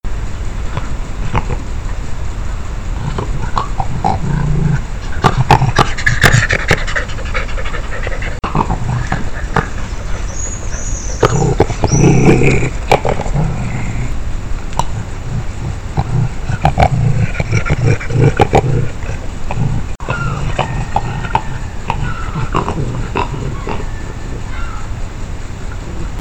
An example of a series of very soft coughs and growls made by a vixen to her cub. This is a composite from several trailcam videos taken over the same night in June 2018 and has been amplified for clarity.
vixen_soft_coughing_to_cub.mp3